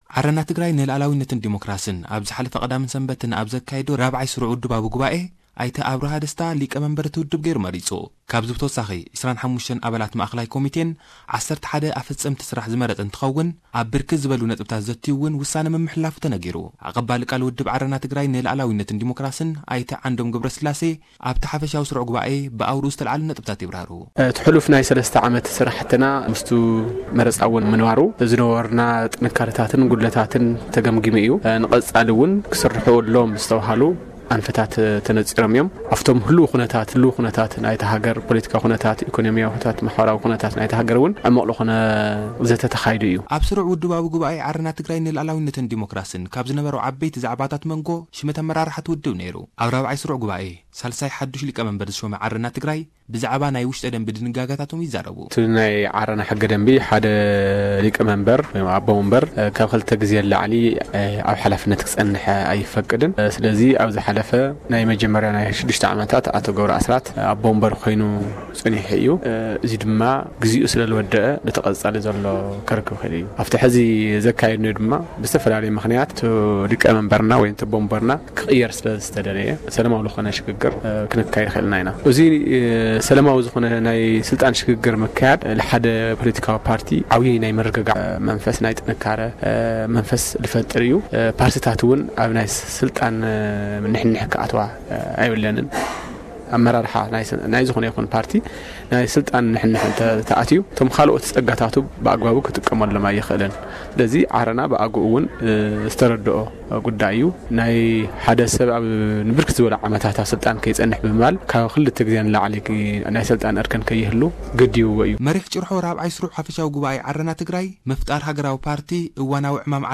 ዓረና ትግራይ ን ሉኣላውነትን ደሞክራሲን ራብ ዓይ ጉባኤ ኣካይዱ፡ ን ኣብርሃ ደስታ ድማ ኣቦ ወምበር ገይሩ መሪጹ። ጸብጻብ ልኡኽና ንምስማዕ ኣብዚ ጠውቑ..